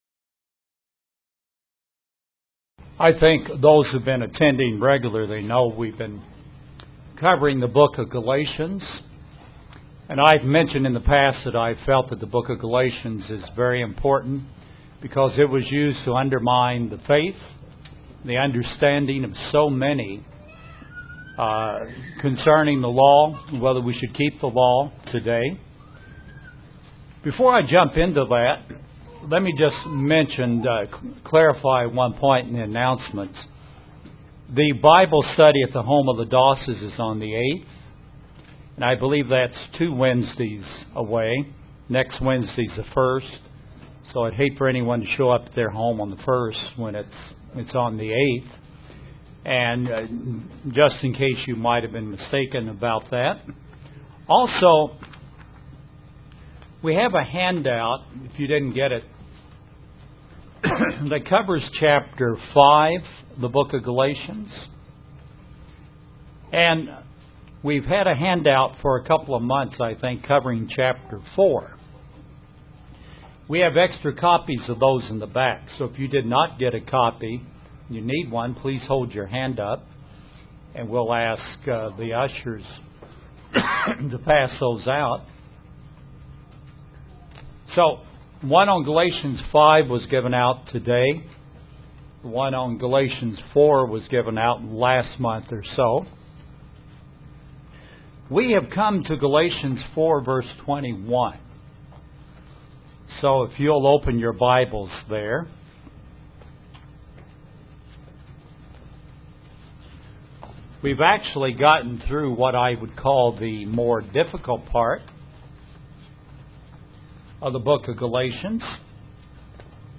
A continuation of a Bible study on the book of Galatians.